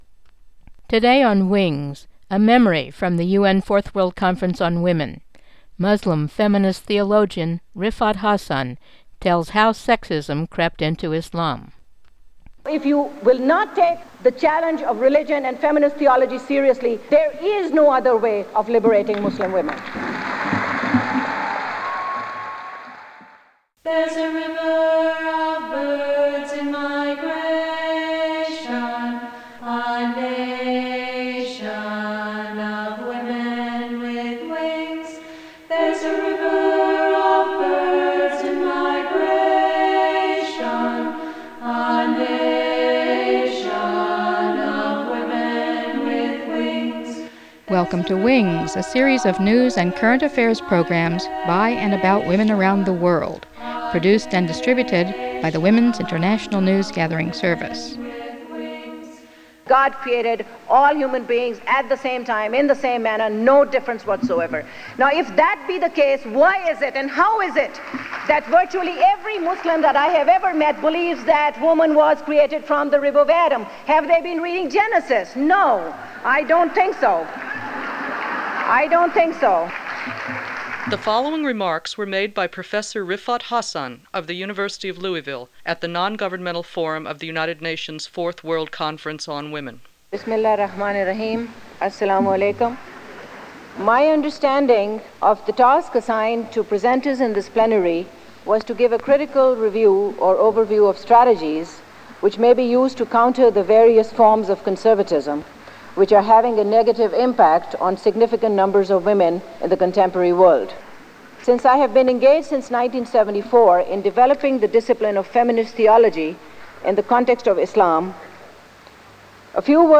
Riffat Hassan, Muslim Feminist Theologian
Mono
in Huairou, China, 1995